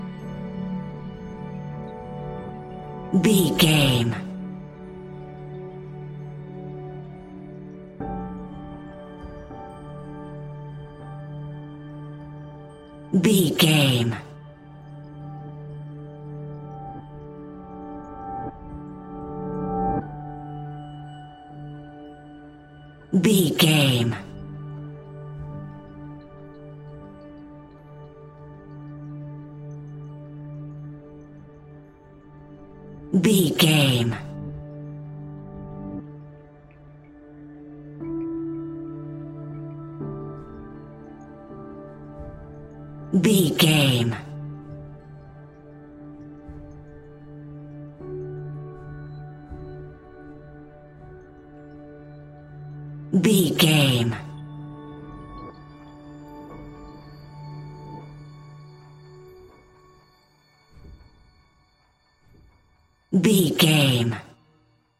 Ionian/Major
D♭
chilled
laid back
Lounge
sparse
new age
chilled electronica
ambient
atmospheric